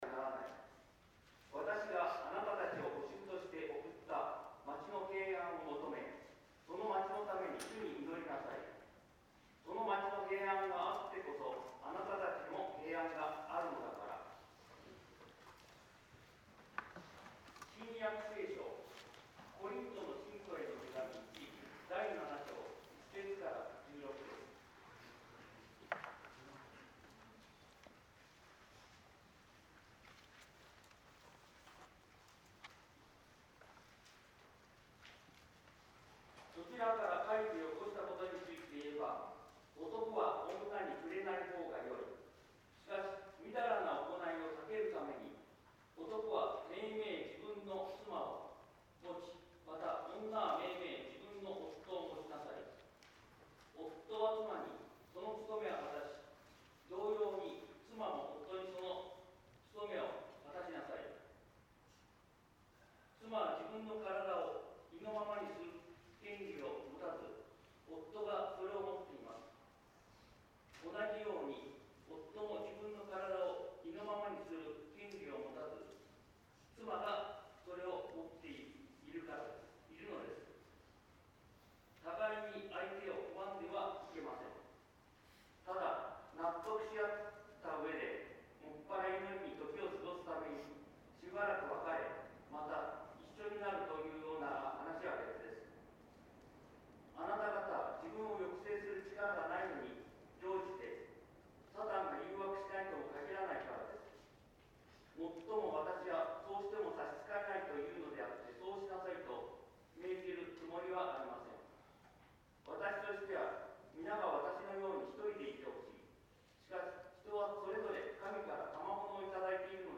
説 教